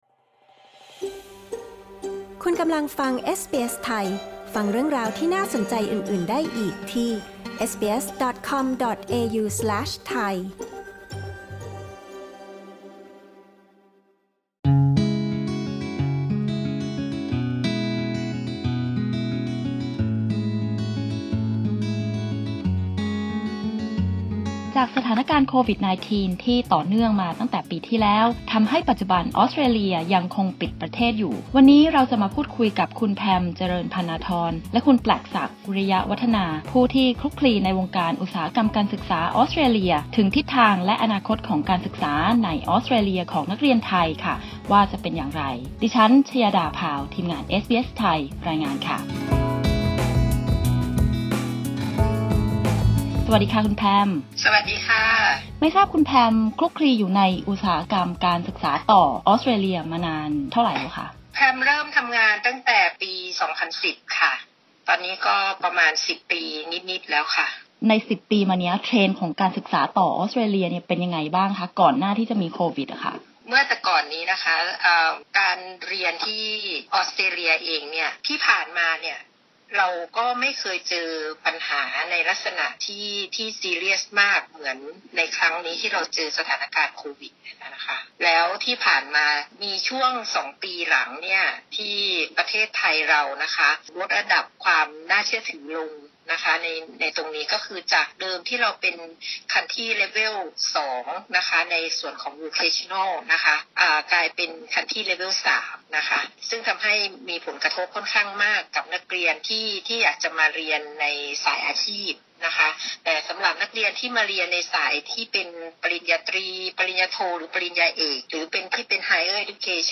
ประเด็นสำคัญในการสัมภาษณ์